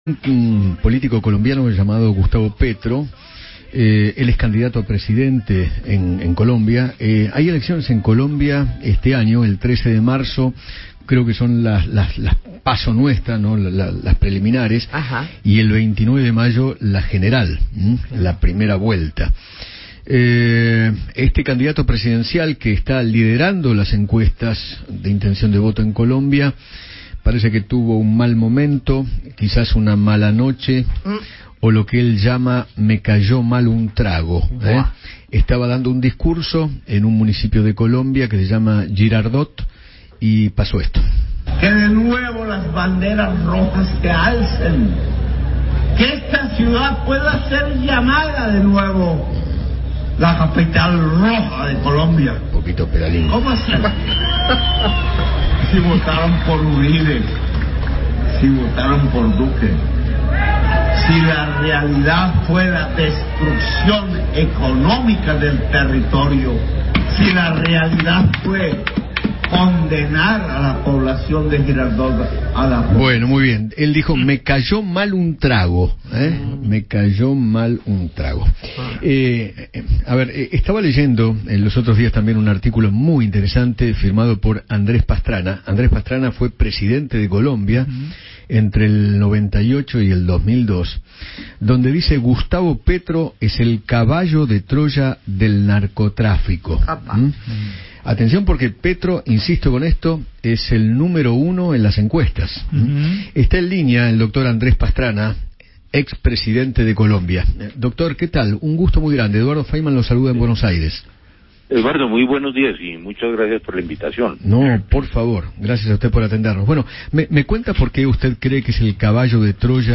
Andrés Pastrana, ex mandatario de Colombia, dialogó con Eduardo Feinmann sobre las próximas elecciones presidenciales en aquel páis y se refirió al artículo que escribió acerca de Gustavo Petro, uno de los candidatos.